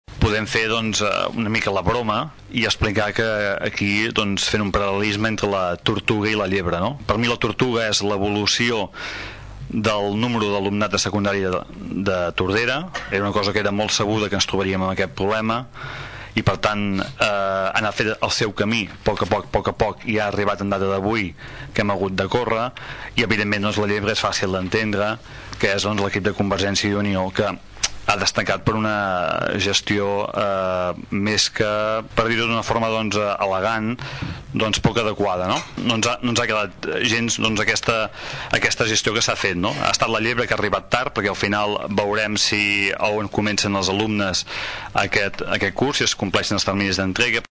El regidor d’ERC, Xavier Pla va lamentar la gestió del govern municipal en aquest tema, que va titllar de poc adequada.